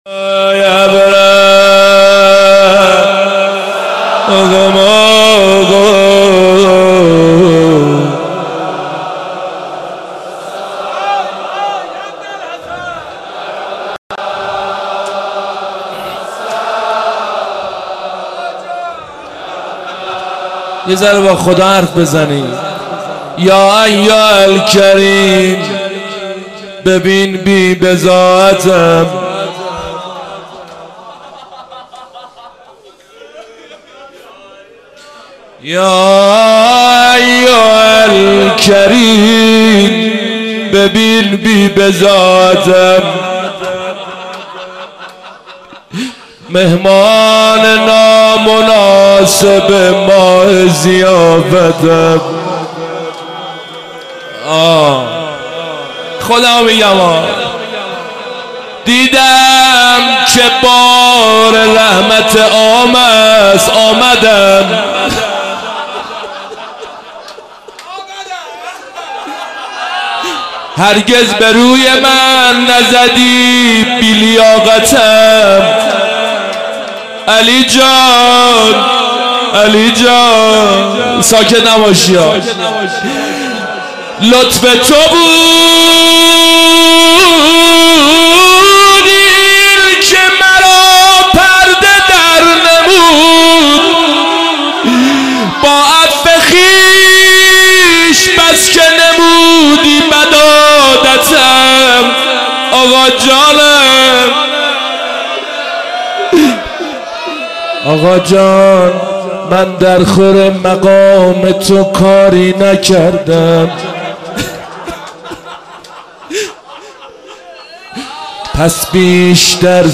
روضه و مناجات